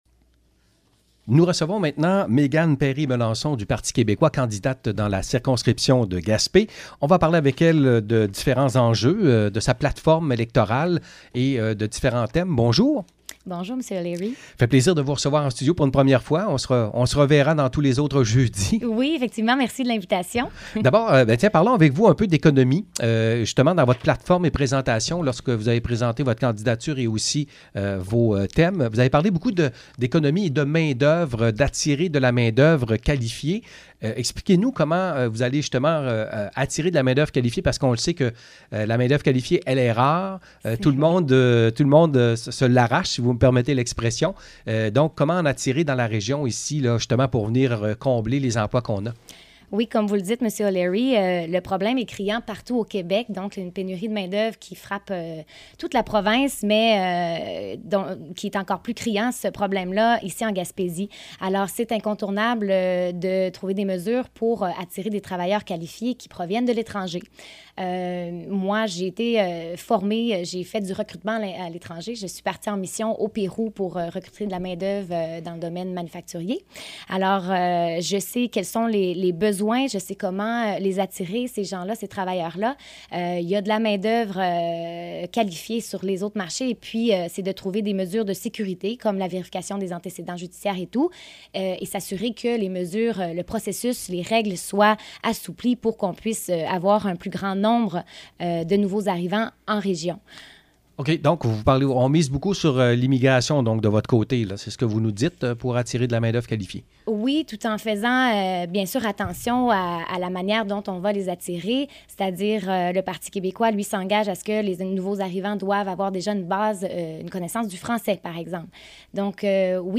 Entrevue avec Méganne Perry Mélançon: